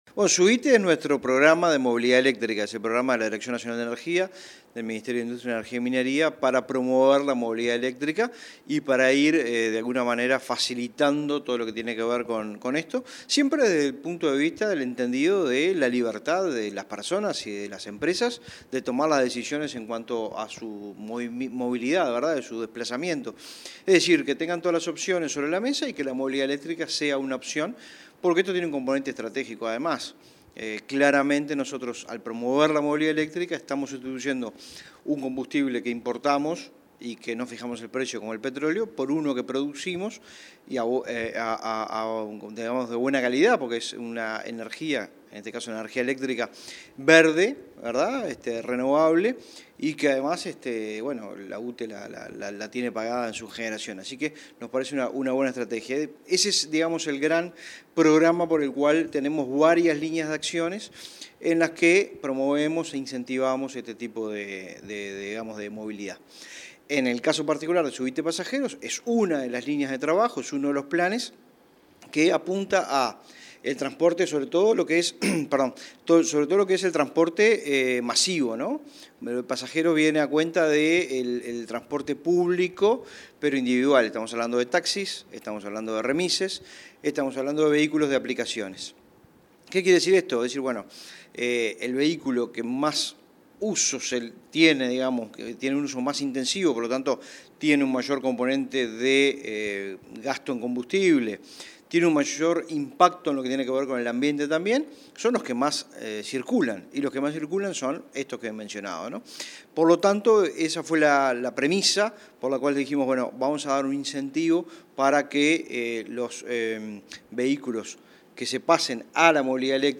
Entrevista al director nacional de Energía, Fitzgerald Cantero